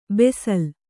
♪ besal